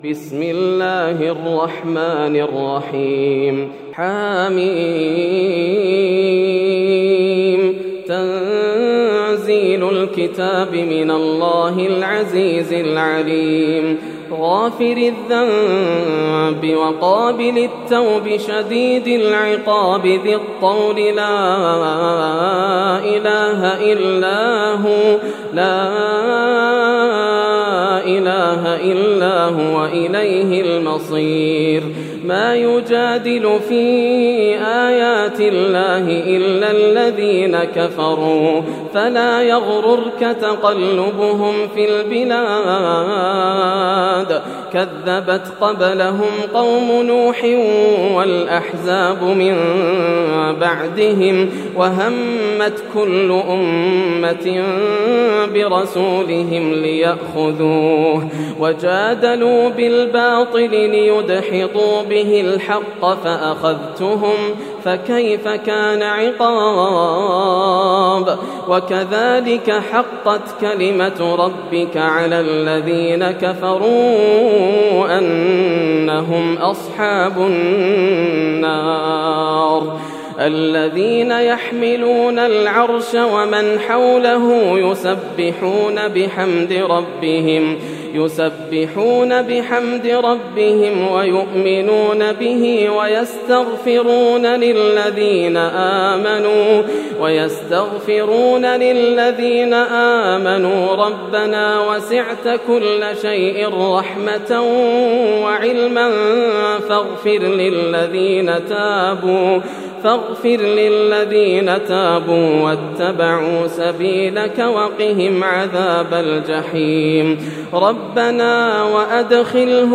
سورة غافر > السور المكتملة > رمضان 1431هـ > التراويح - تلاوات ياسر الدوسري